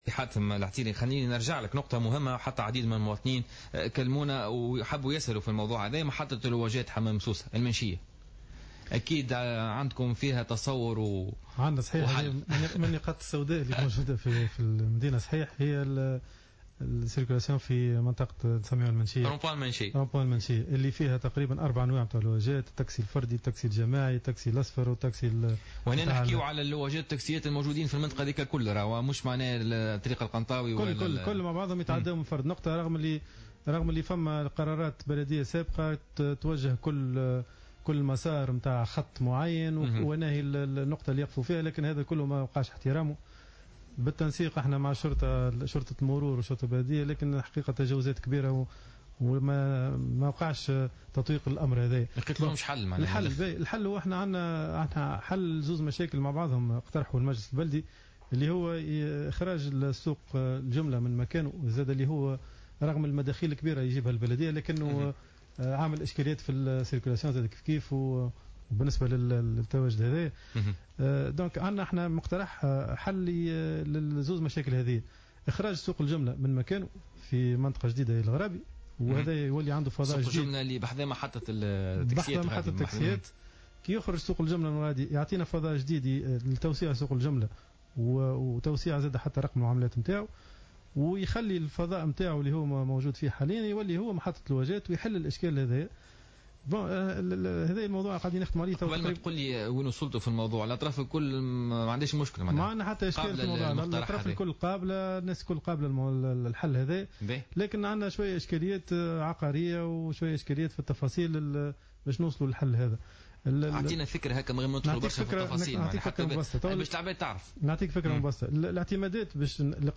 أكد رئيس النيابة الخصوصية بحمام سوسة حاتم العتيري ضيف بوليتيكا اليوم الجمعة 18 سبتمبر 2015 وجود مخطط جاهز لنقل سوق الجملة بحمام سوسة من مكانه وتحويله إلى منطقة الغرابي سهلول ليتم استغلال مكانه الحالي كفضاء لمحطات التاكسي الجماعي و "اللواج" الموجودون حاليا على مستوى مفترق "المنشية" وذلك للحد من الاكتظاظ المروري .